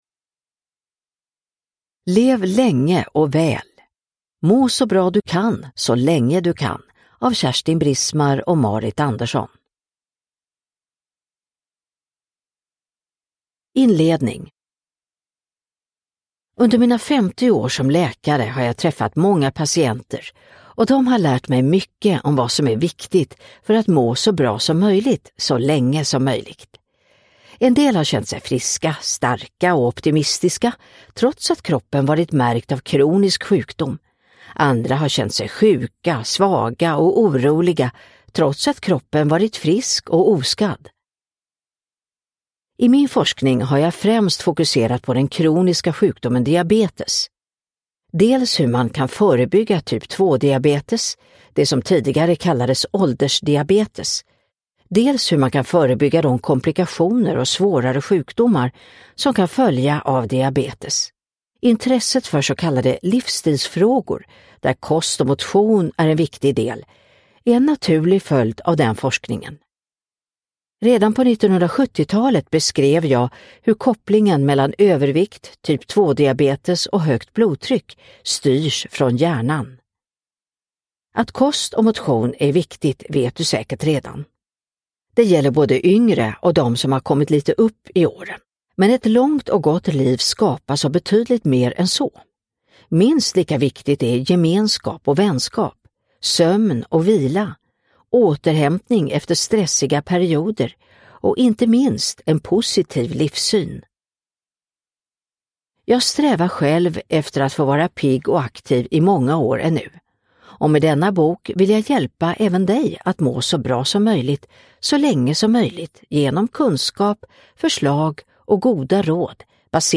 Lev länge & väl : professorns bästa tips om kosten, motionen och sömnen – Ljudbok – Laddas ner